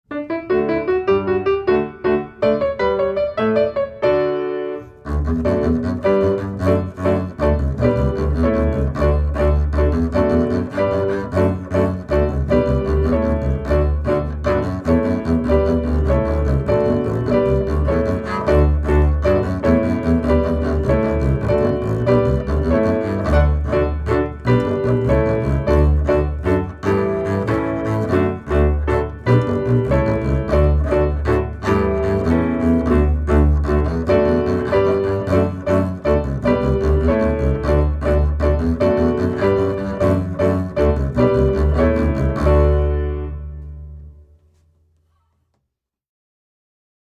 Voicing: String Bass